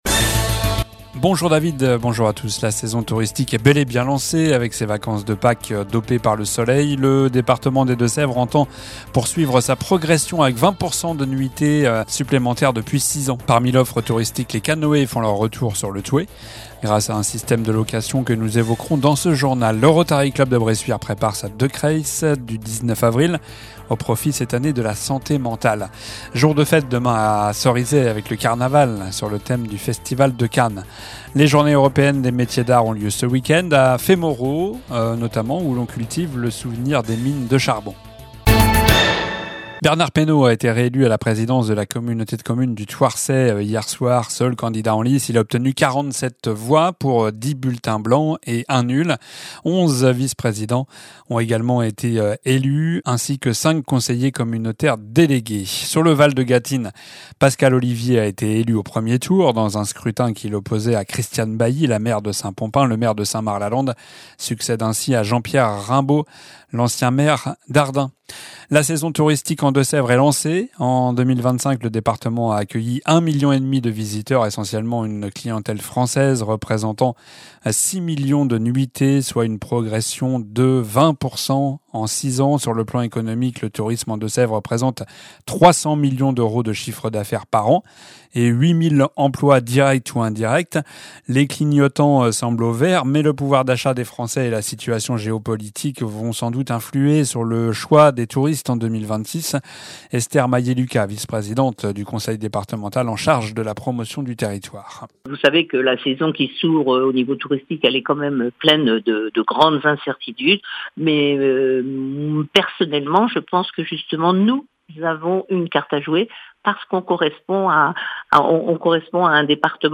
Journal du vendredi 10 avril (midi)